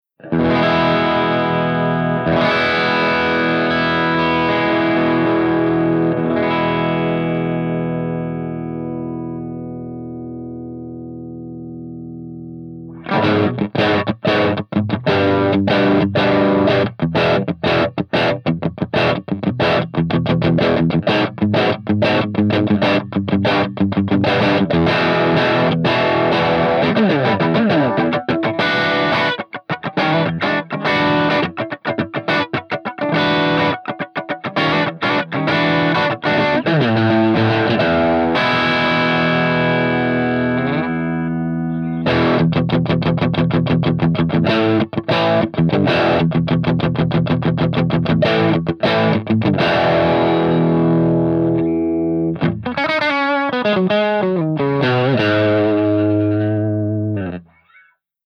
135_ROCKERVERB_CH2CRUNCH_V30_SC